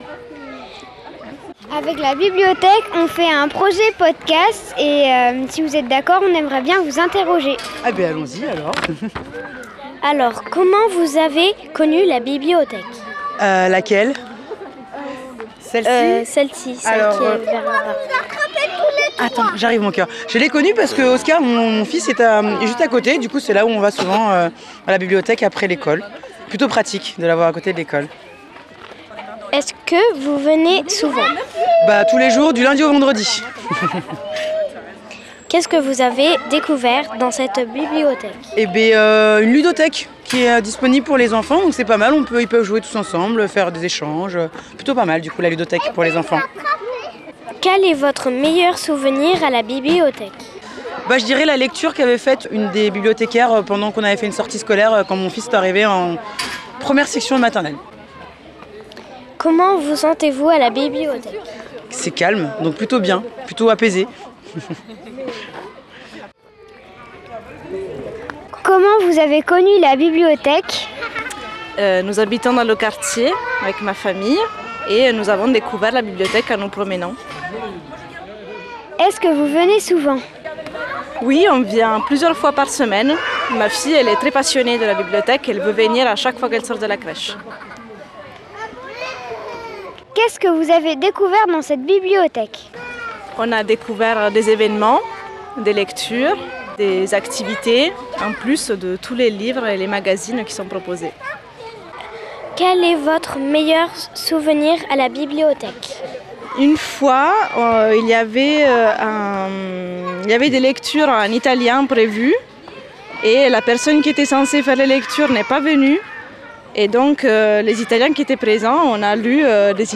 il était une bonnefoy- reportage .mp3